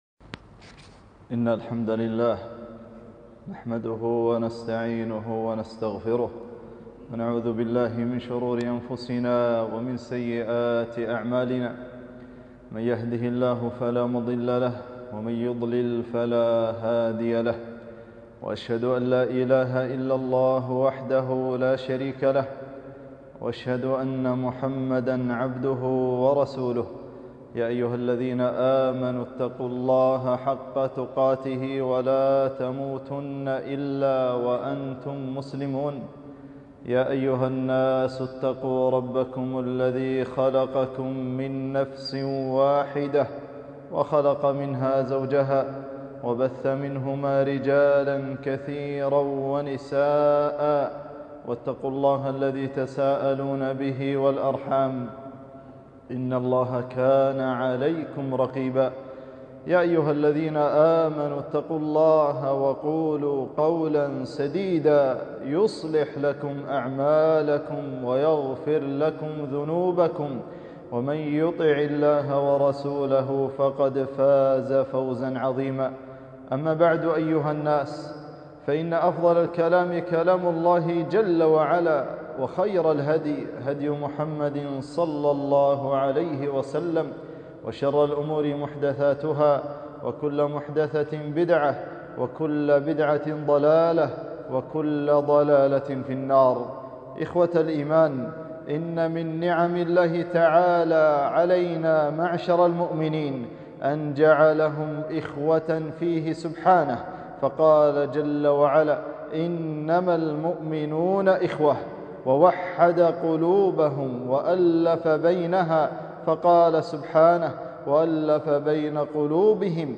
خطبة - إخوة الإسلام والمحبة في الله